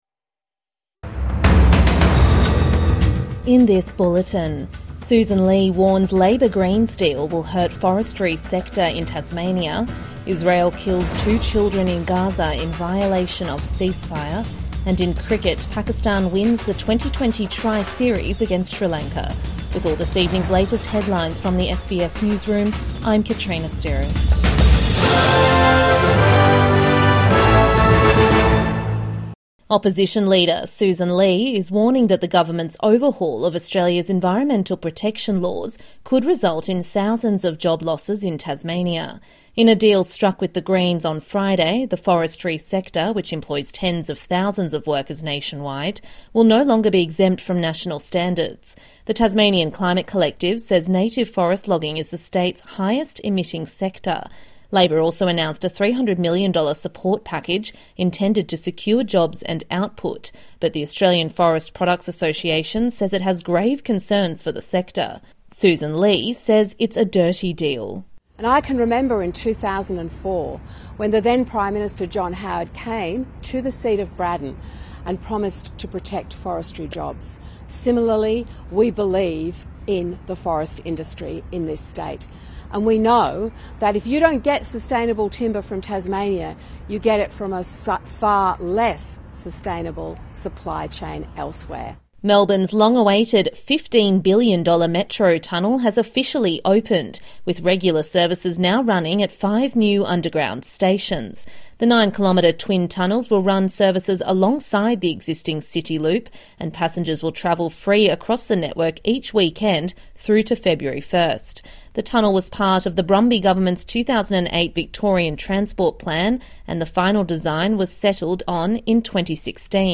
Israel kills two children in Gaza in ceasefire violation | Evening News Bulletin 30 November 2025